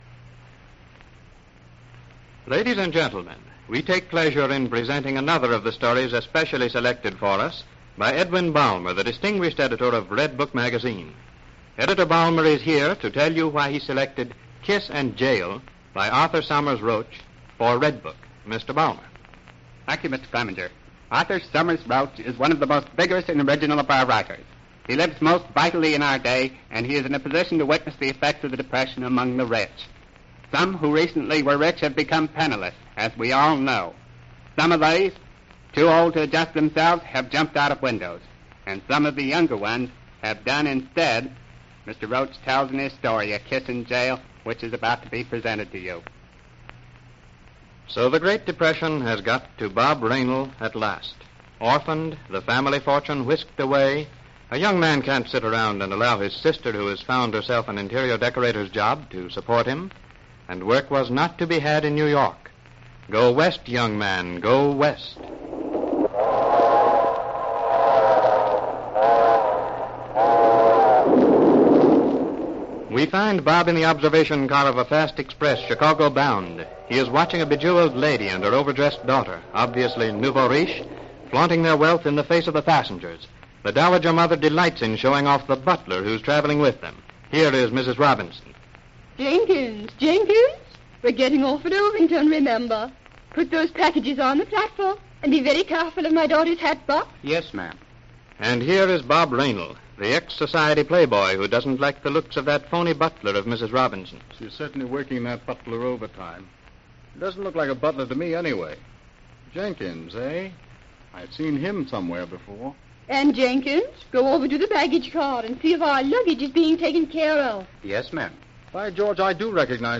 "Redbook Dramas" was a captivating radio series that brought stories from Redbook Magazine to life during the golden age of radio.